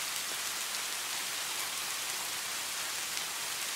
rain.mp3